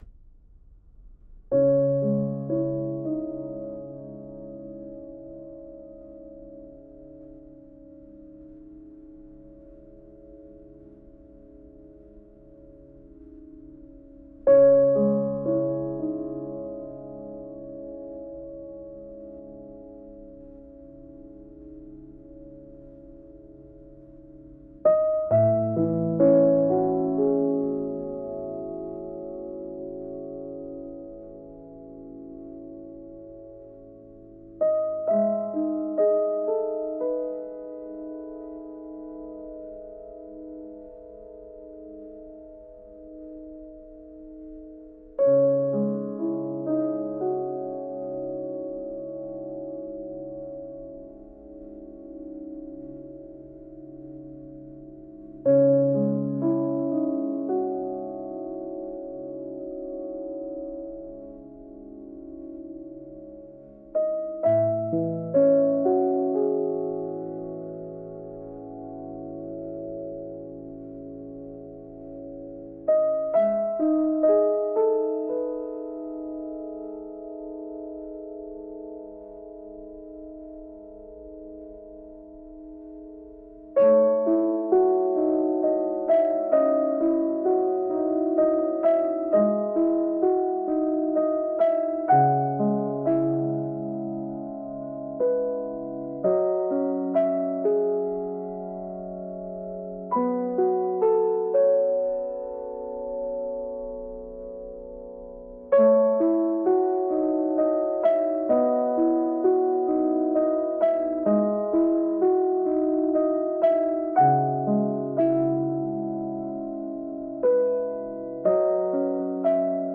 「幻想的」